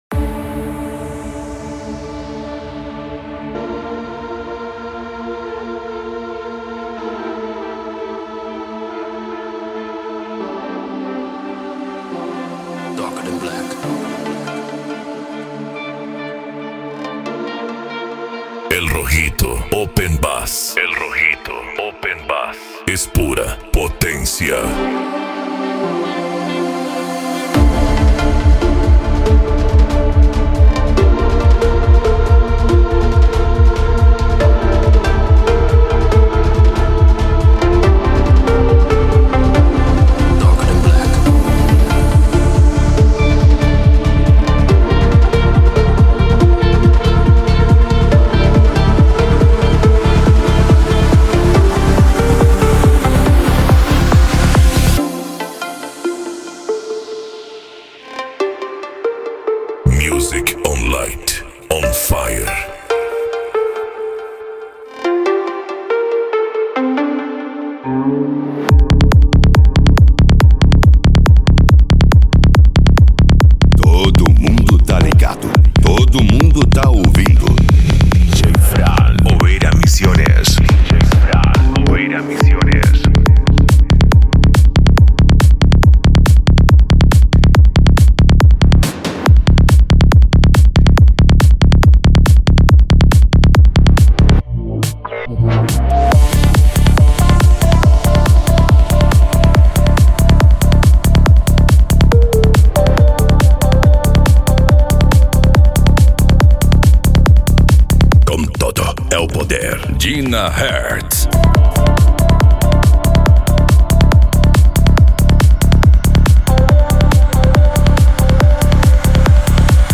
Electro House
Psy Trance
Remix